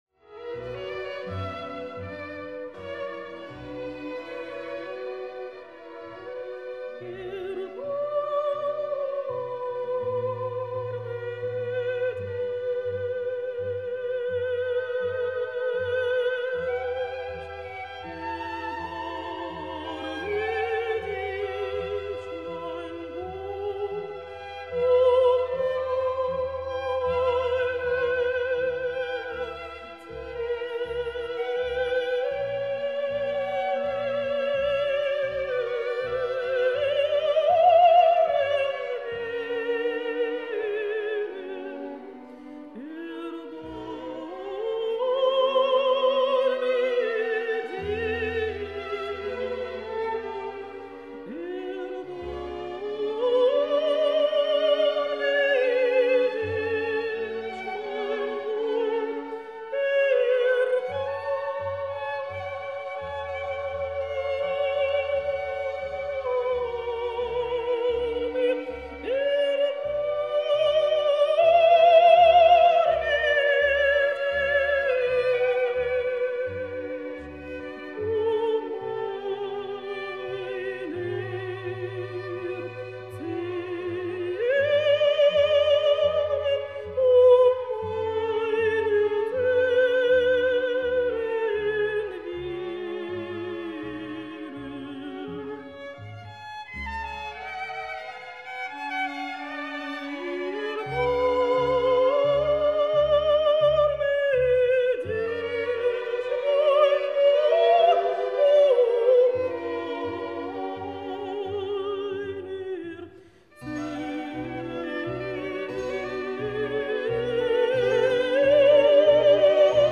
Плач Петра в её исполнении (с блистательной скрипичной партией Марка Лубоцкого) звучит очень прозрачно и собранно, при этом максимально детально.
Валентина Левко и ансамбль солистов оркестра Большого театра
партия скрипки